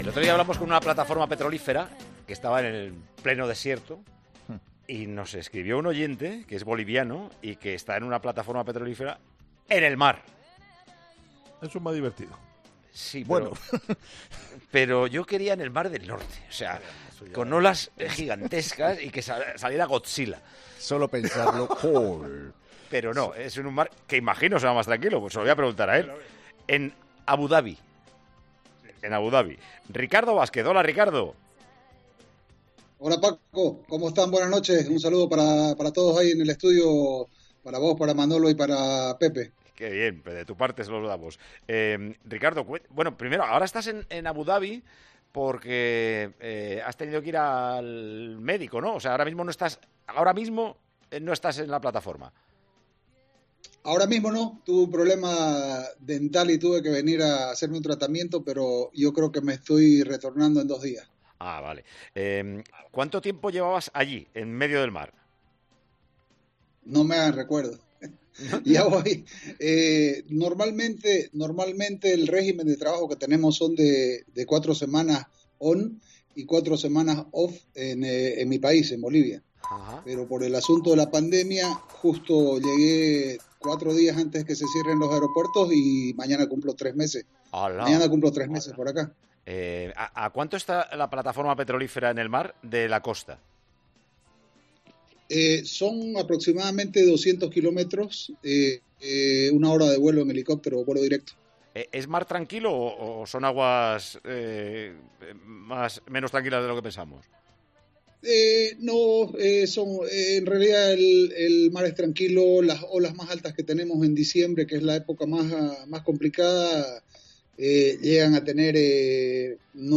AUDIO: Este boliviano lleva casi 90 días en una plataforma a 200 kilómetros de Abu Dhabi y ha contado su experiencia en Tiempo de Juego: "Tenemos...